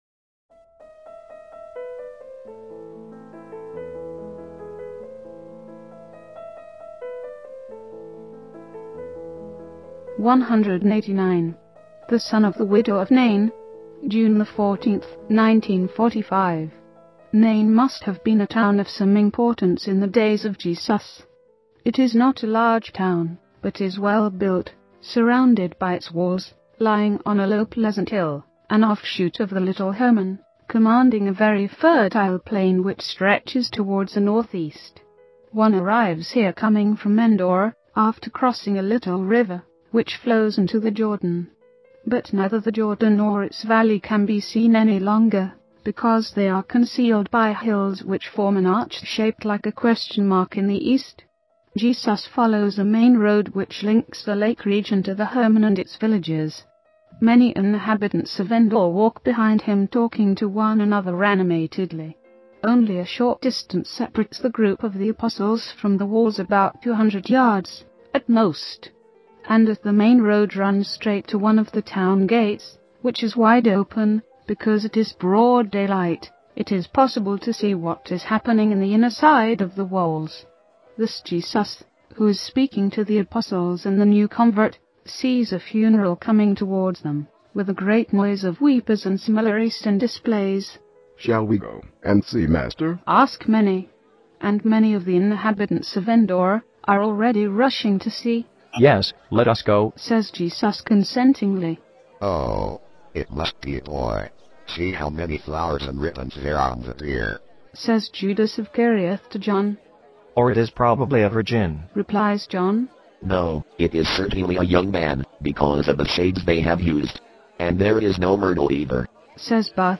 Text-to-Speech Menu
02-189_TTS.mp3